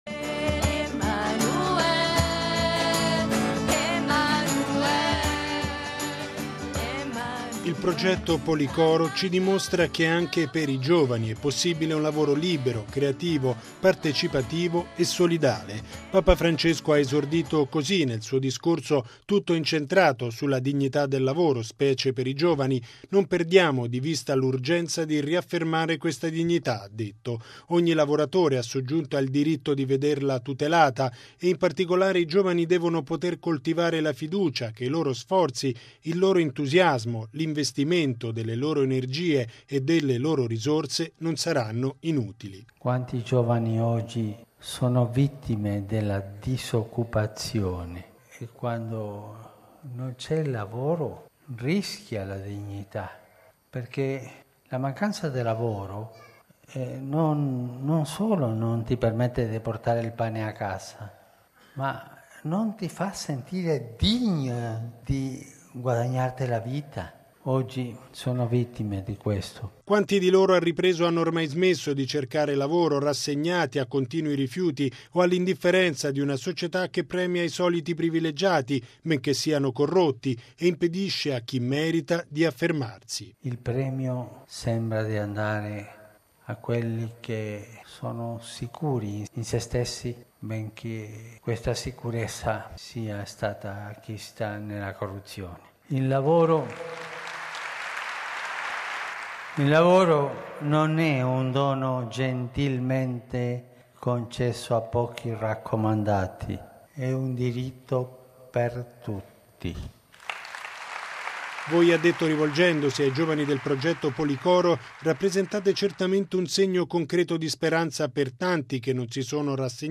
Bollettino Radiogiornale del 14/12/2015